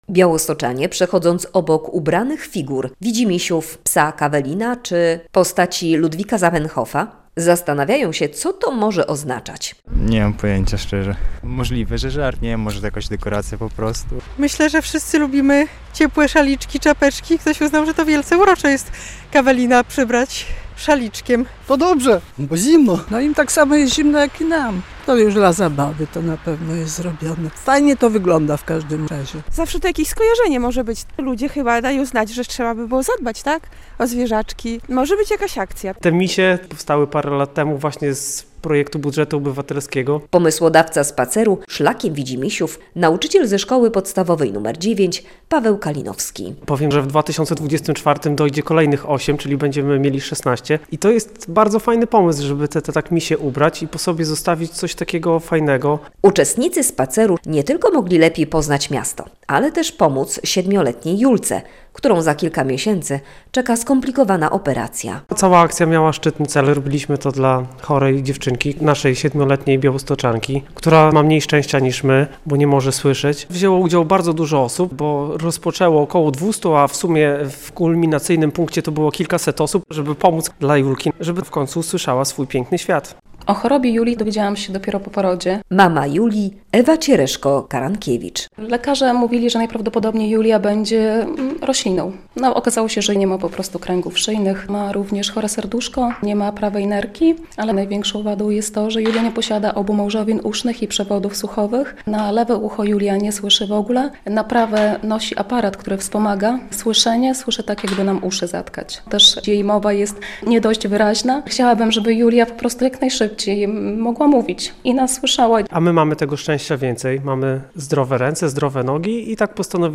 W Białymstoku można podziwiać ubrane figurki WidziMisiów - relacja